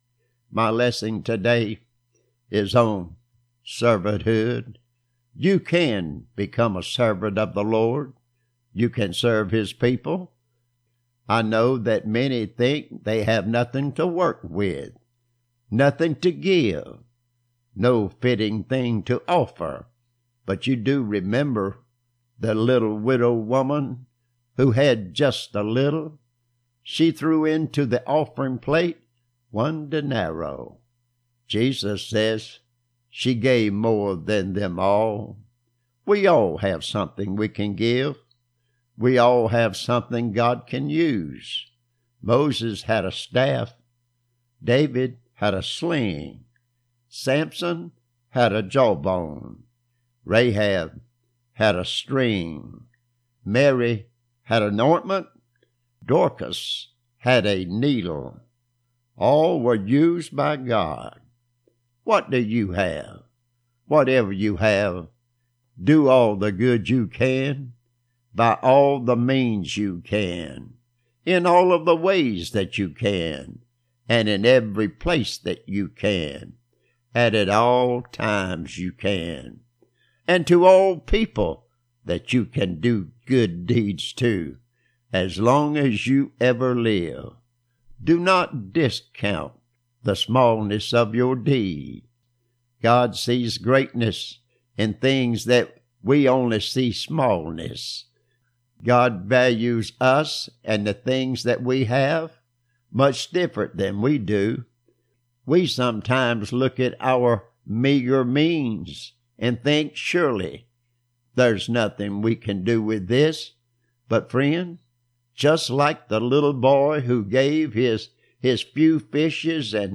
Recent Sermons